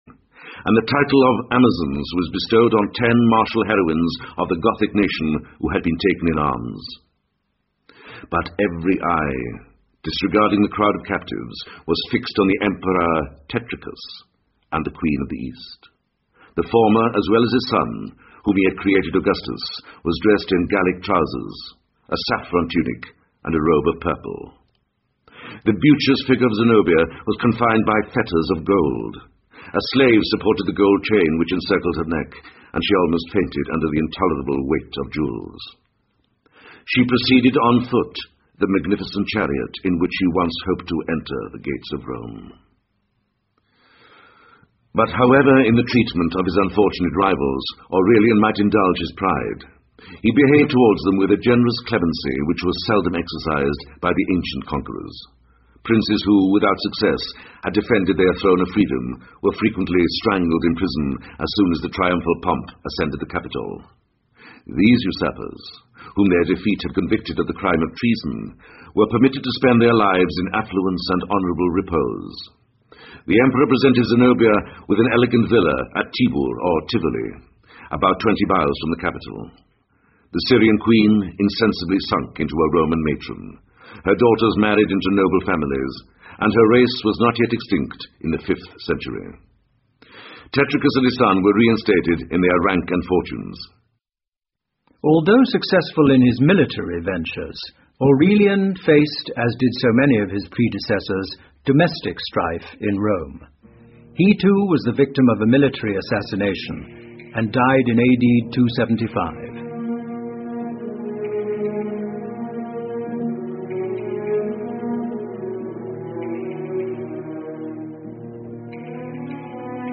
在线英语听力室罗马帝国衰亡史第一部分：36的听力文件下载,有声畅销书：罗马帝国衰亡史-在线英语听力室